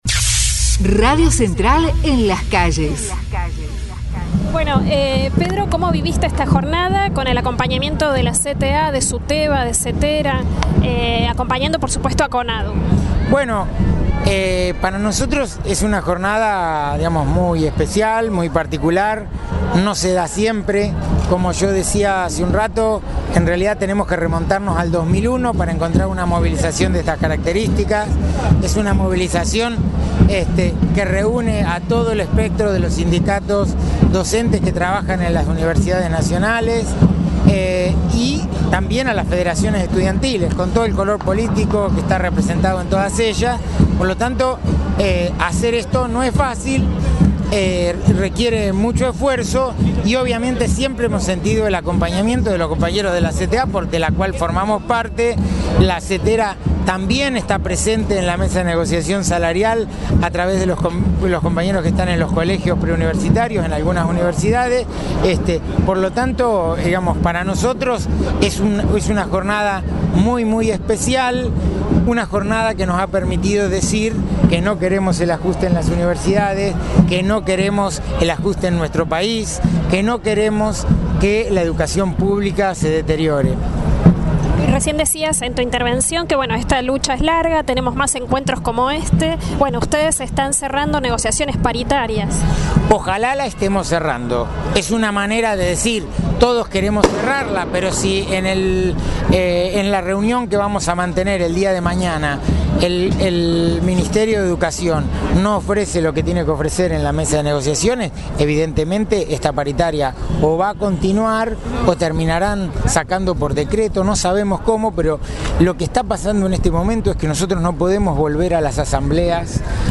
Marcha nacional en defensa de la Universidad Pública.